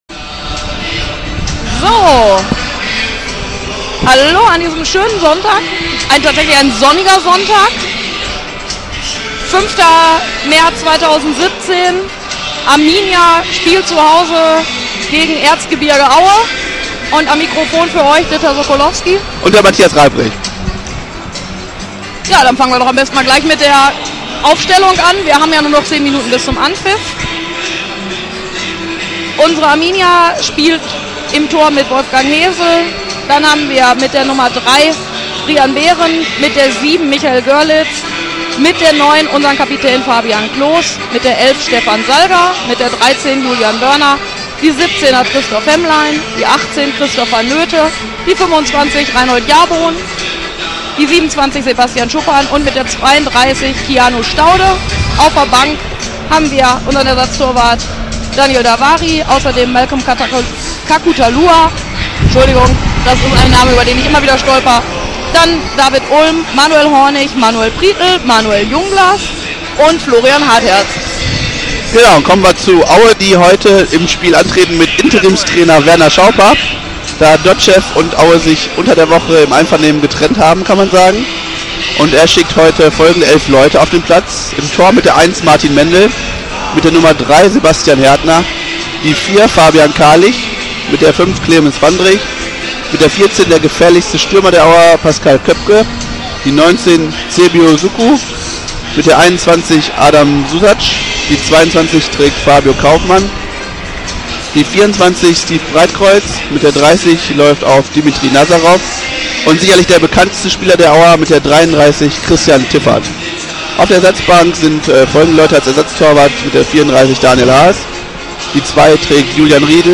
Die Spiel-Reportage im Player
Ort Schüco Arena, Bielefeld